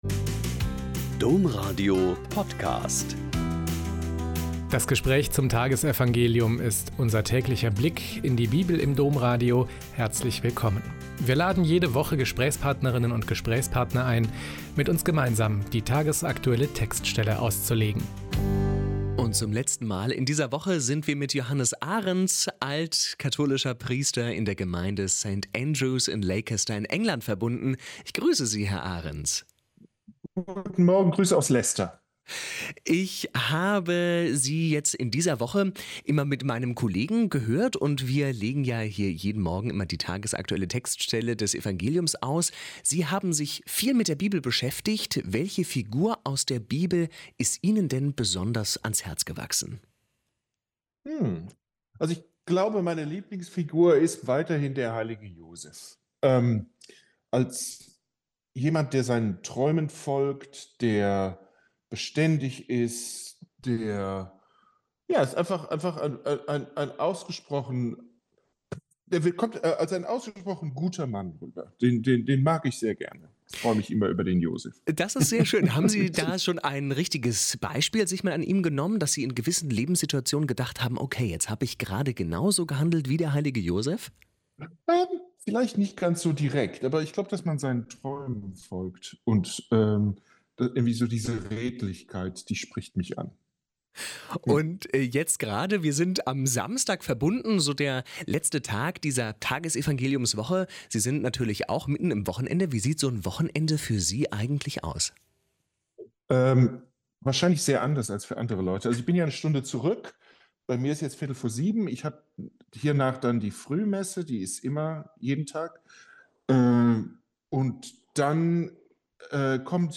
Mk 10,13-16 - Gespräch